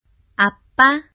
発音と読み方
아빠 [アッパ]
1．日本語の小さな「ッ」が入るイメージ、2．息が極力出ないよう喉を締め付け、絞るイメージ。舌を奥に引き、口からではなく喉から発声する、3．中国語っぽくトーンを高く